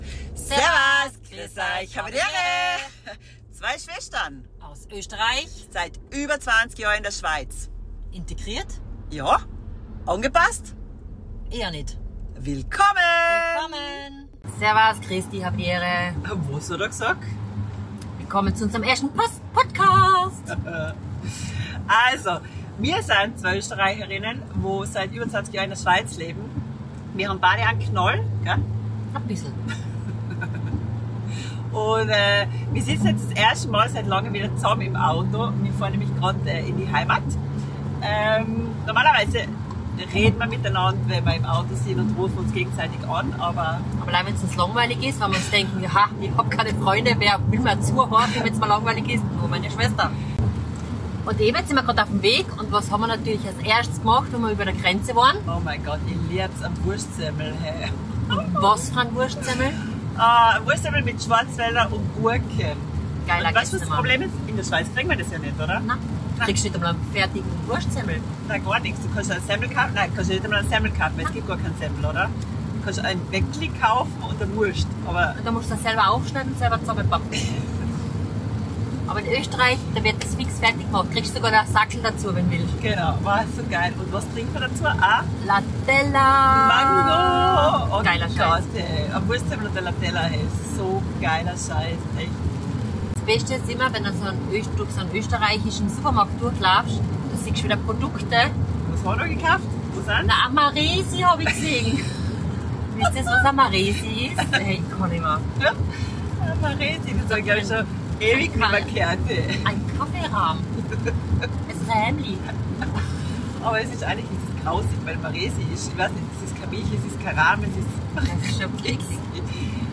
mir zwei im Auto, im Dialekt ohne Filter, ehrlich und sicher lustig 2 Östereicherinnen in der Schweiz, seit über 20 Jahren. Die eine in den Bergen in der Sonne, die andere im Unterland im Nebel. alte Geschichten, unser Humor den teilweise nur wir verstehen und ehrliches Gelaber